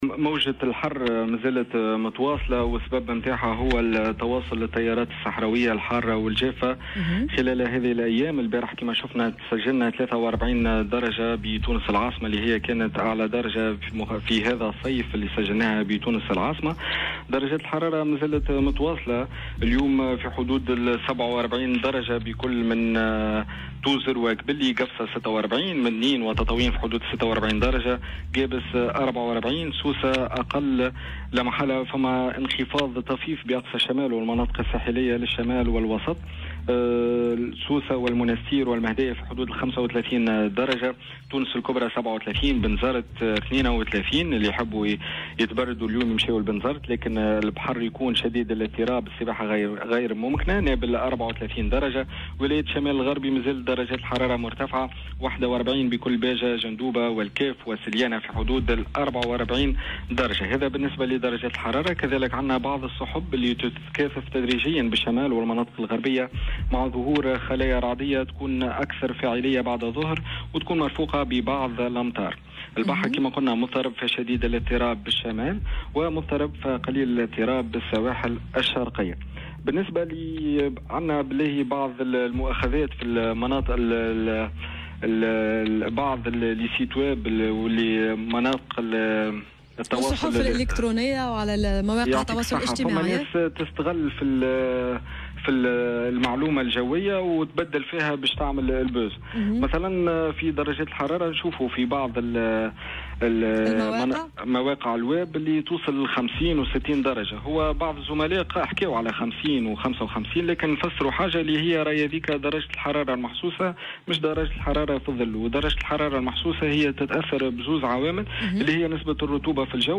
في تصريح اليوم لـ"الجوهرة أف أم".